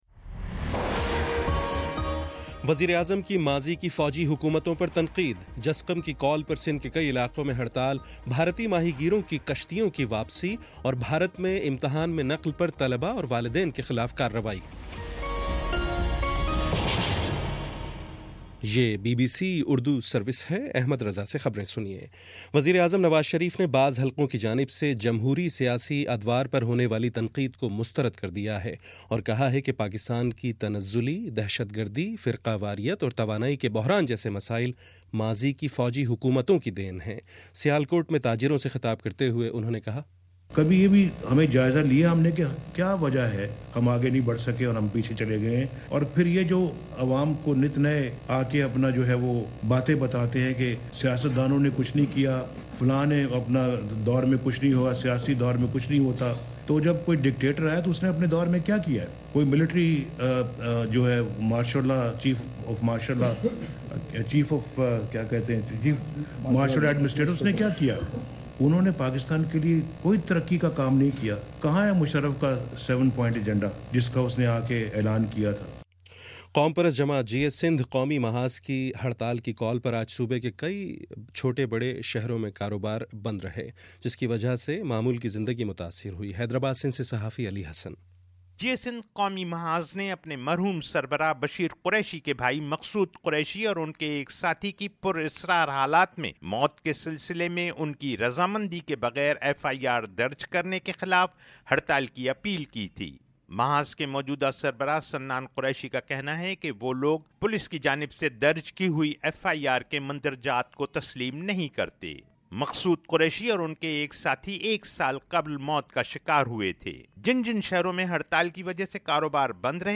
مارچ 21: شام پانچ بجے کا نیوز بُلیٹن